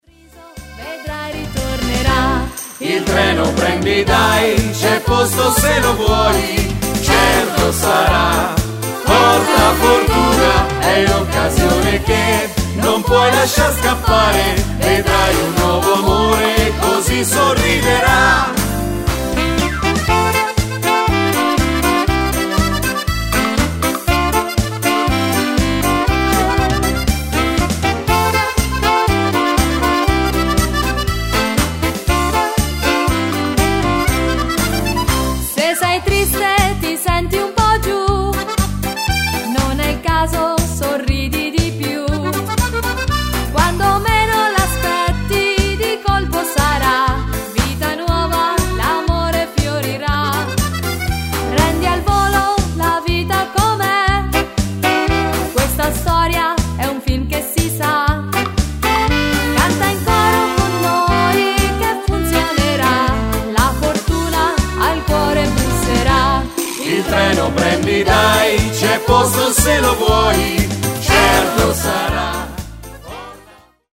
Ritmo allegro
Donna / Uomo